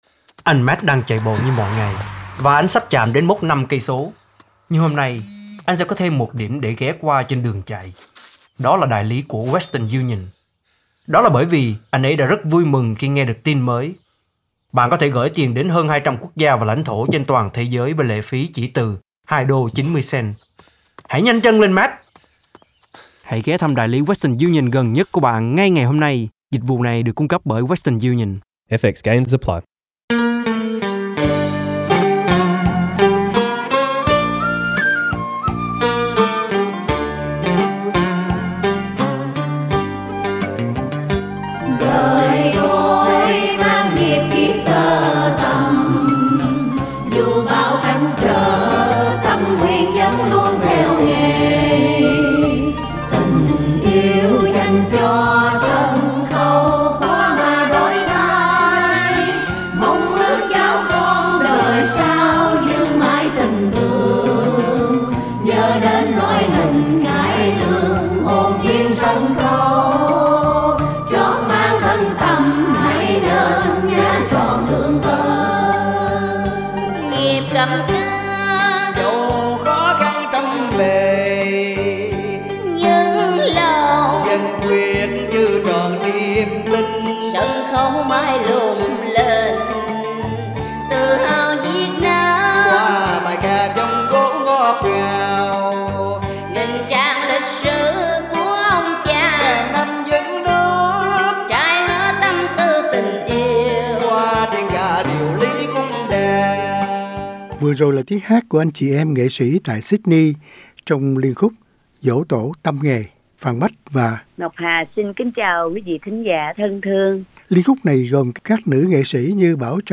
Cổ Nhạc